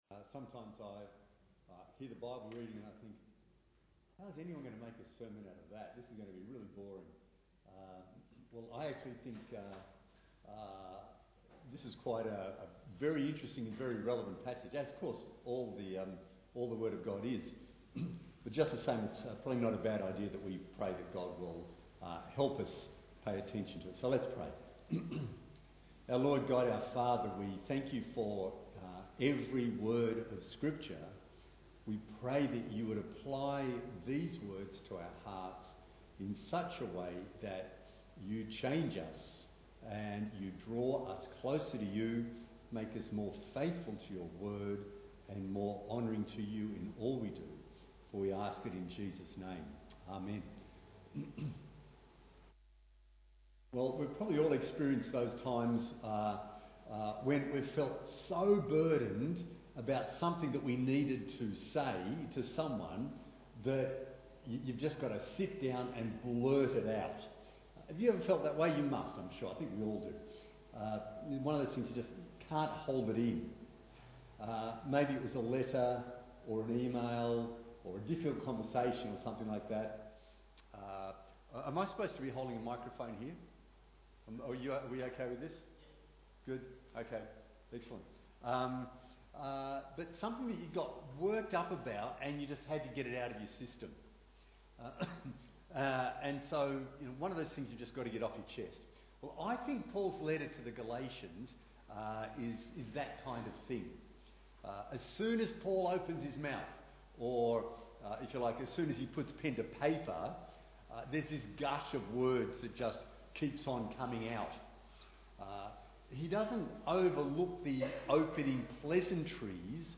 Bible Text: Galatians 1: 11 – 2:10 | Preacher